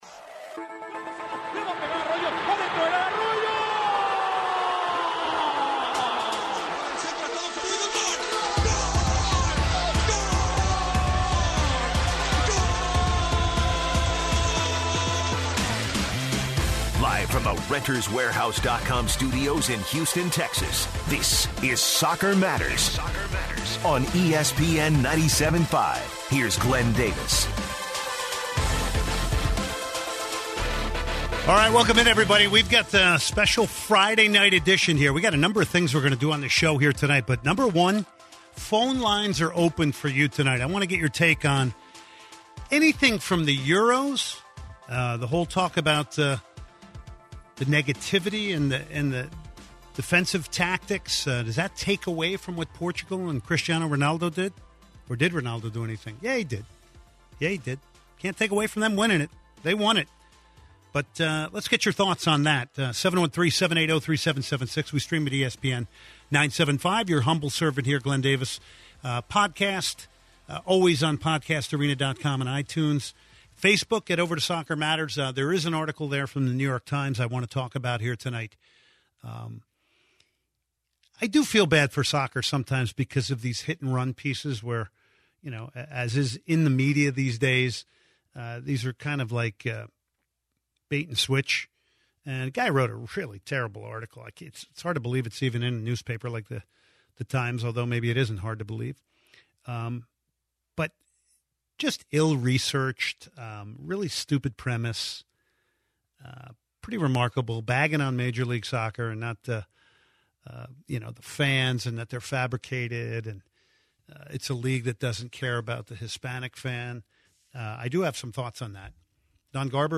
takes calls on Dynamo and South American soccer.